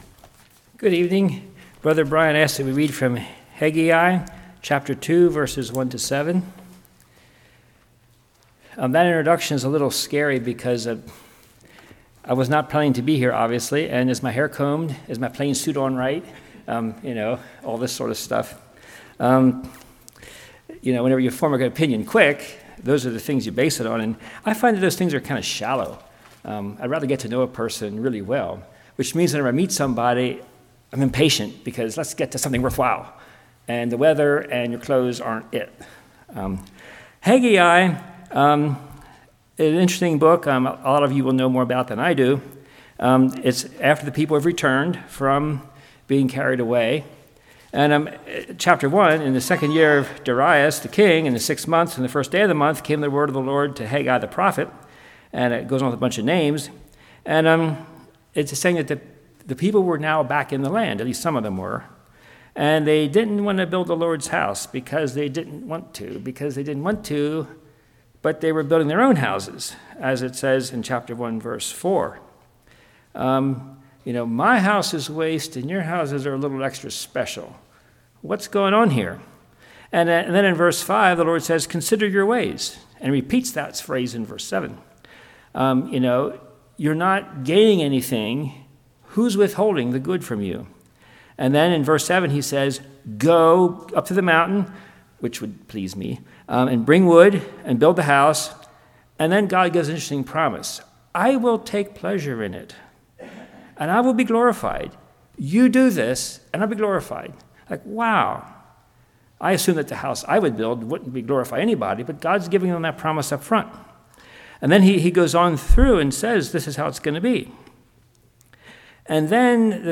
Haggai 2:1-7 Service Type: Evening Do You Desire Jesus Christ?